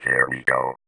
VVE1 Vocoder Phrases
VVE1 Vocoder Phrases 25.wav